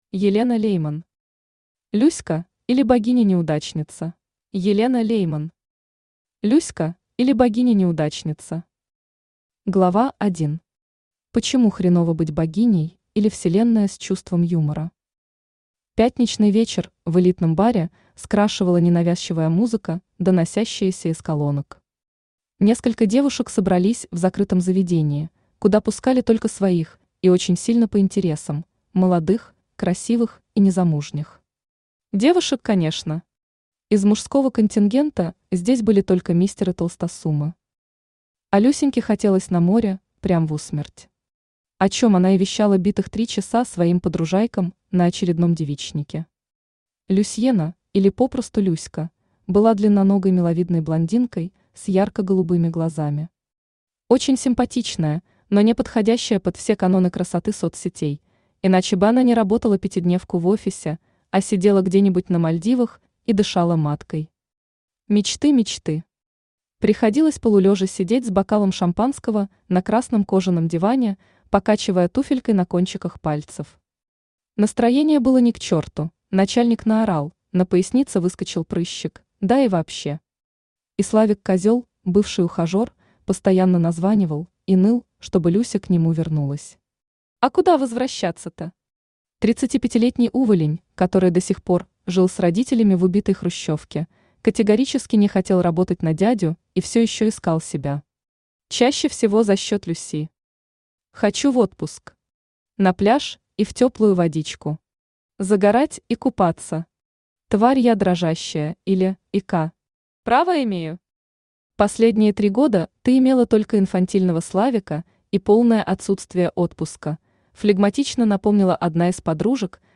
Aудиокнига Люська, или Богиня-неудачница Автор Елена Лейман Читает аудиокнигу Авточтец ЛитРес.